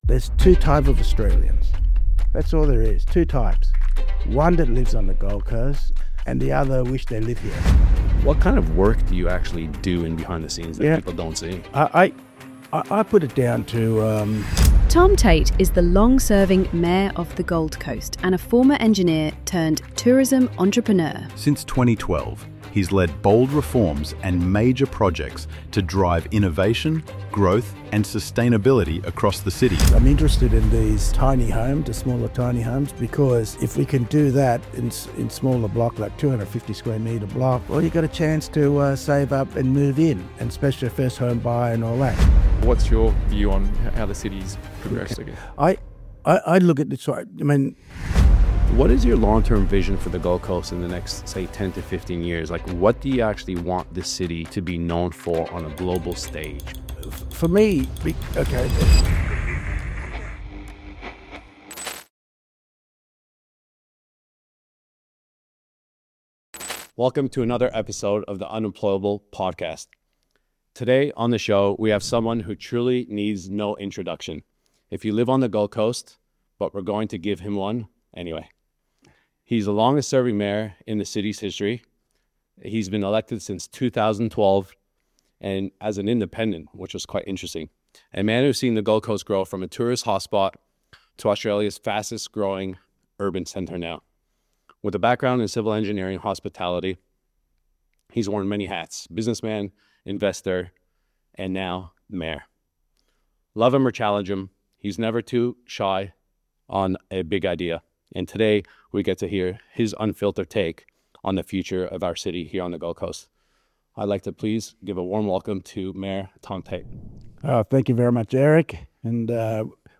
1 Why Property Developers Are Flocking To The Gold Coast | Mayor Tom Tate Interview 49:01
1 Why Property Developers Are Flocking To The Gold Coast | Mayor Tom Tate Interview 49:01 Play Pause 1d ago 49:01 Play Pause Play later Play later Lists Like Liked 49:01 In this episode, we sit down with Mayor Tom Tate to unpack the Gold Coast’s explosive growth and what it really means for property developers. From game-changing town planning reforms to the rise of build-to-rent, prefab towers, and tiny homes, Tom shares his unfiltered take on the opportunities ahead.